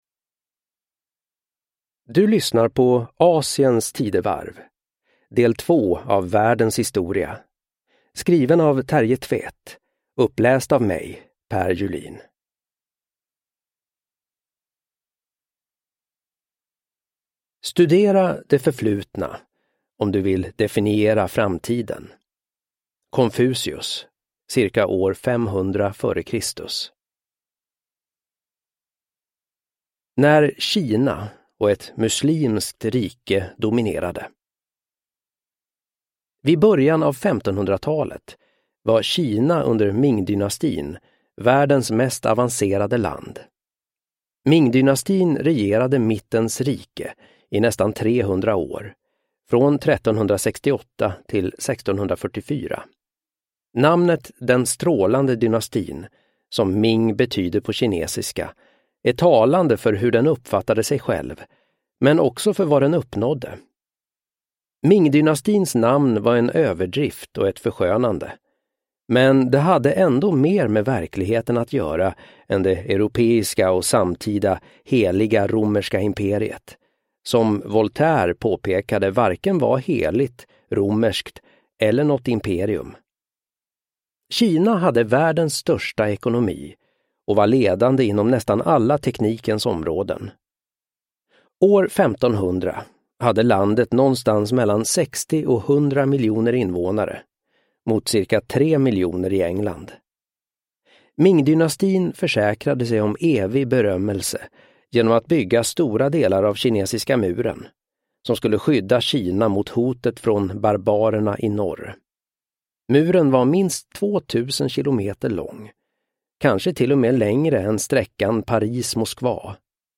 Världens historia: Del 2 – Asiens tidevarv – Ljudbok – Laddas ner